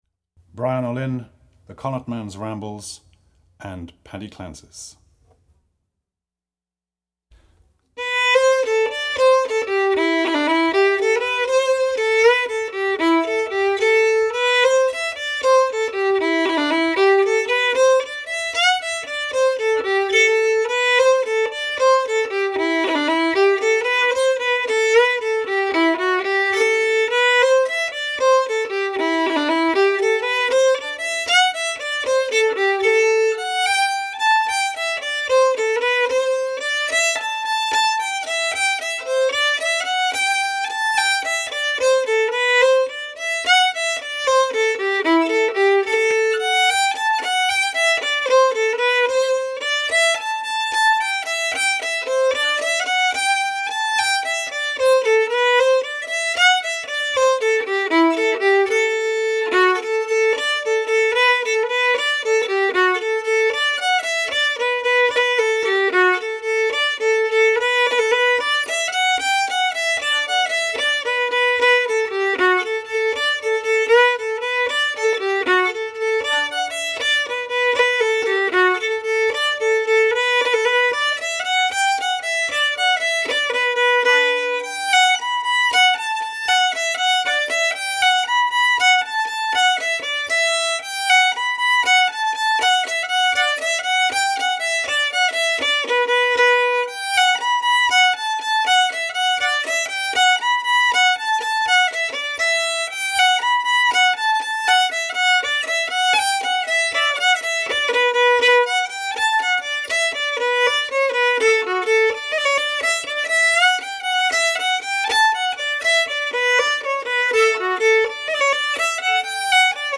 FIDDLE SOLO Fiddle Solo, Celtic/Irish,Jig
DIGITAL SHEET MUSIC - FIDDLE SOLO